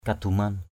/ka-d̪u-man/ (cv.) paduman pd~mN [A, 264]